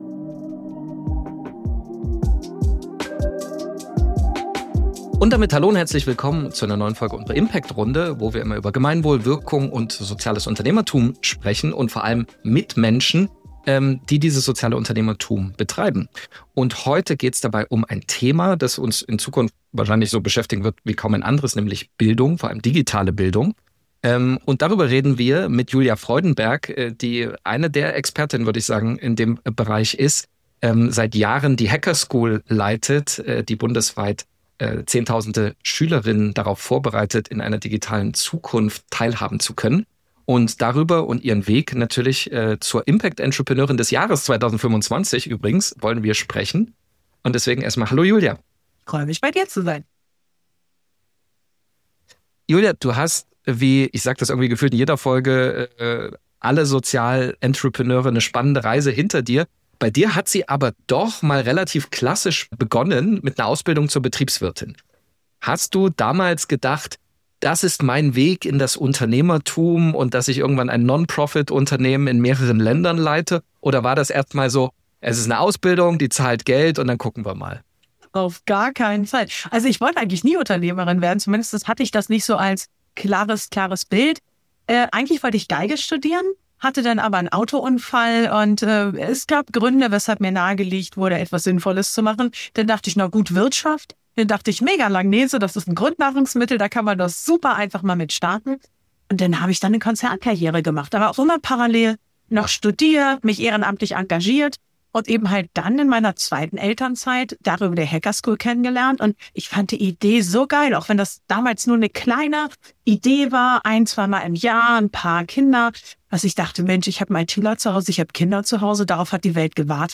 Digitale Bildung und soziale Innovation: Ein Gespräch mit